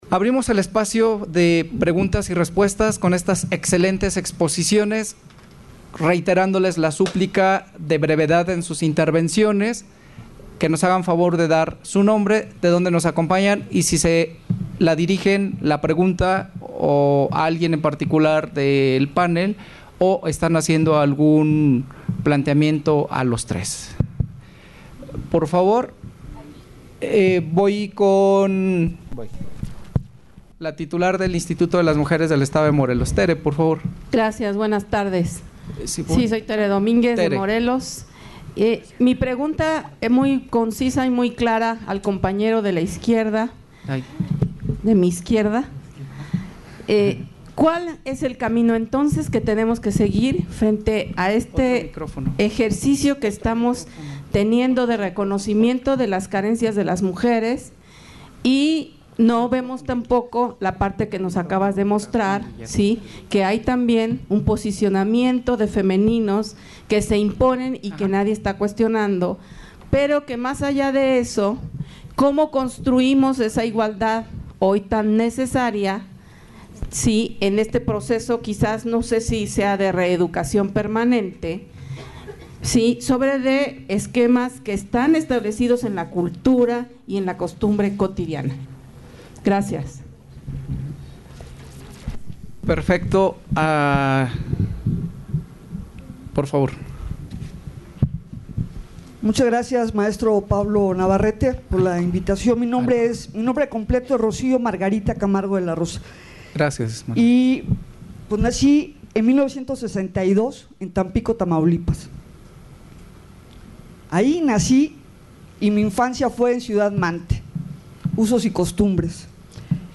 XIX Encuentro Nacional de las Áreas Jurídicas de los Mecanismos Estatales para el Adelanto de las Mujeres.